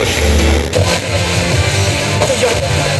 80BPM RAD3-R.wav